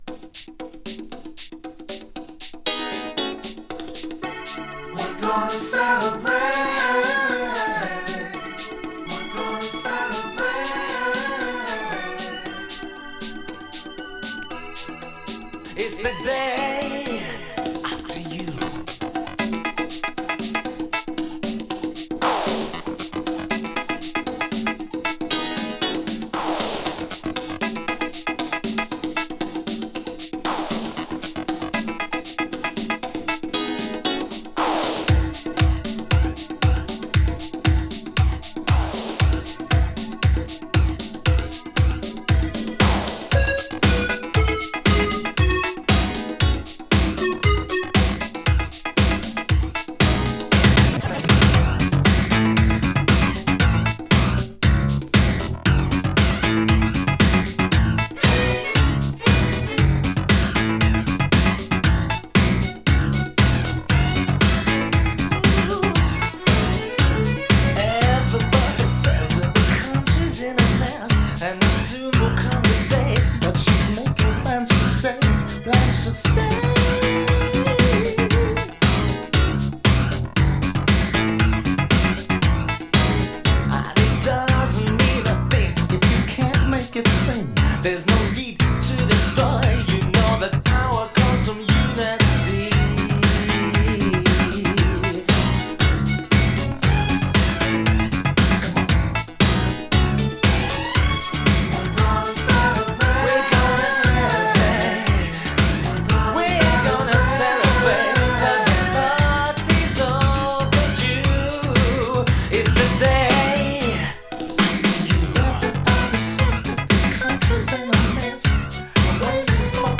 両アーティストの持ち味がクロスオーヴァーしたアーバン&エレクトリックなシンセ・ブギー！
【BOOGIE】【SYNTH POP】